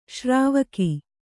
♪ śrāvaki